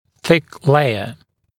[θɪk ‘leɪə][сик ‘лэйэ]толстый слой